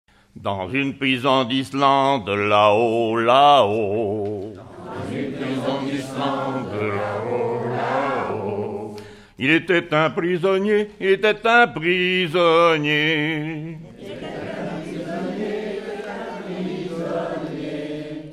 Saint-André-Goule-d'Oie
Genre laisse
Pièce musicale inédite